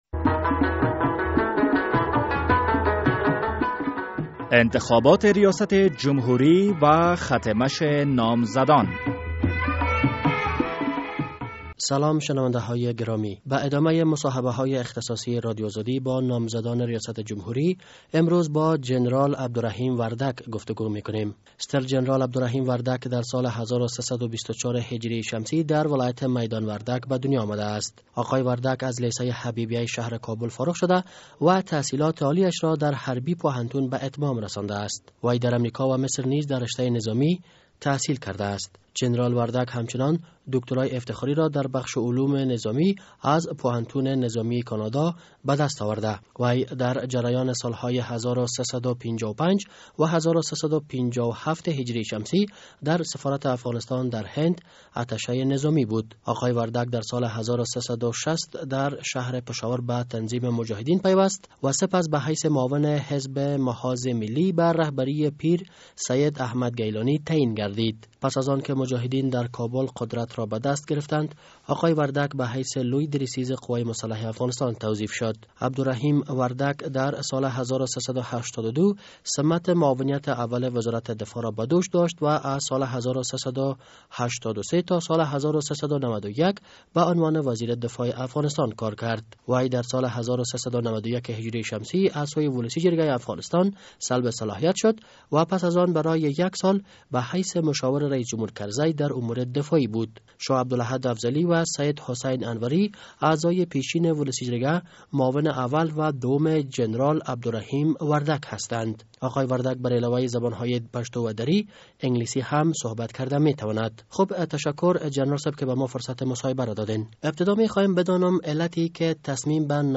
مصاحبهء اختصاصی با جنرال عبدالرحیم وردک